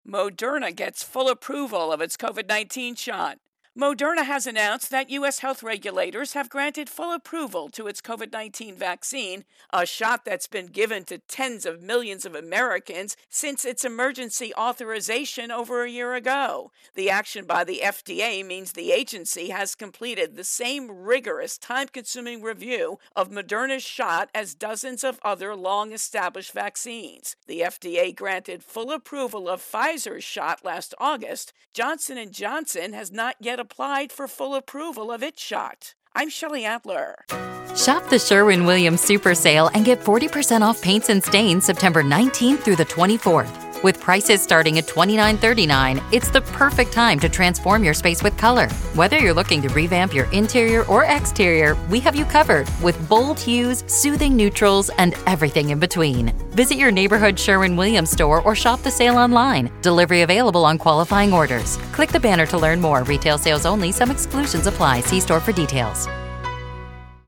Moderna intro and voicer